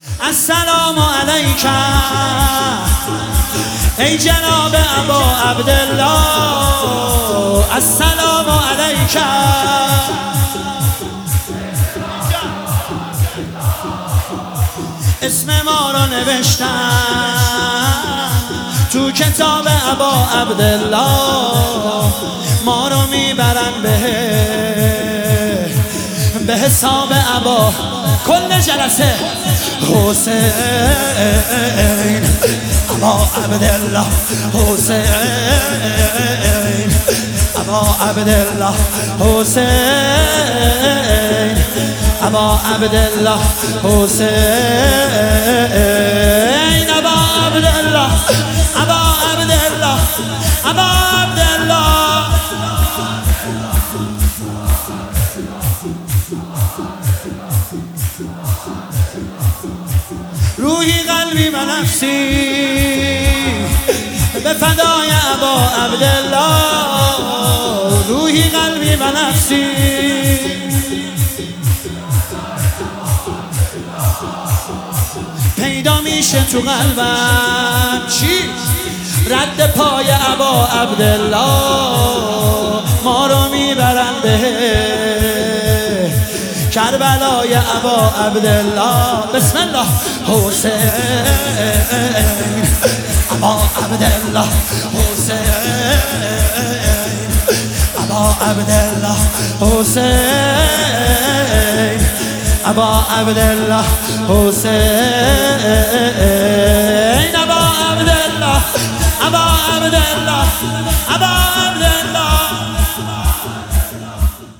مداحی شور
حرم شهدای گمنام شهرستان ملارد
فاطمیه دوم 1403